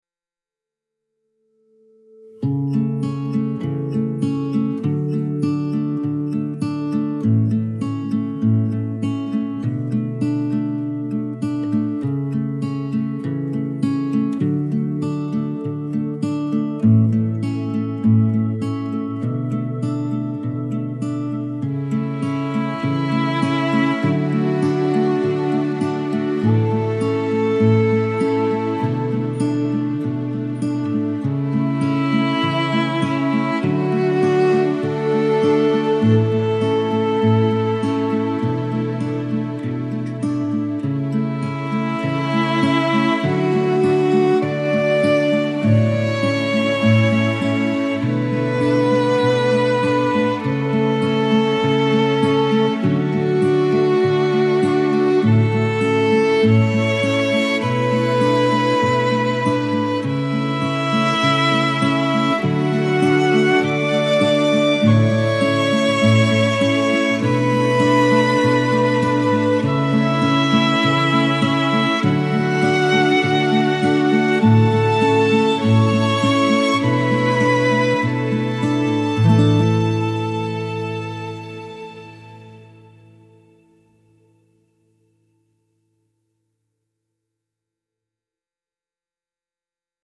enduring legacy theme with gentle acoustic guitar and strings